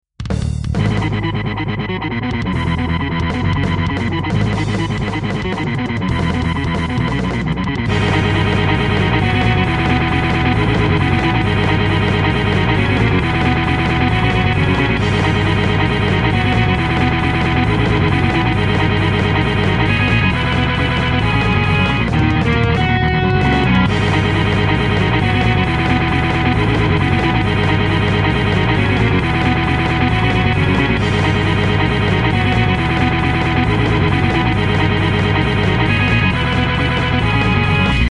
ロック。無駄にヘビー。